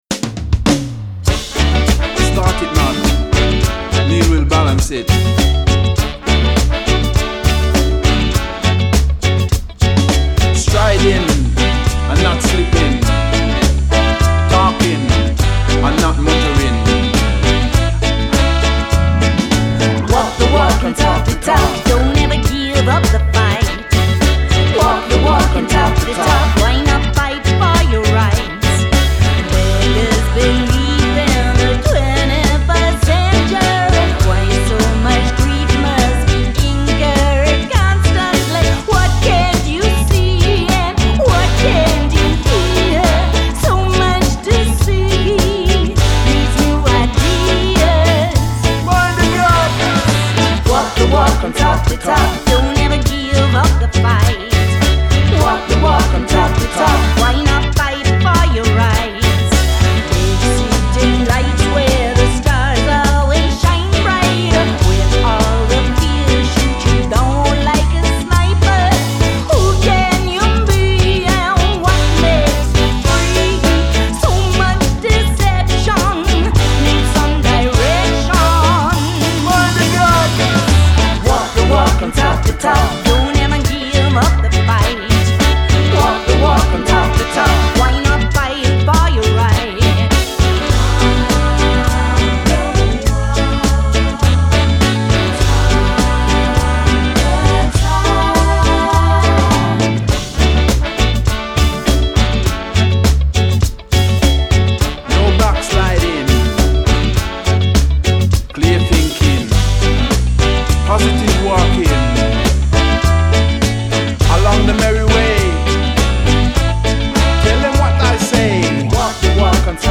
Genre: Ska, Reggae, Dub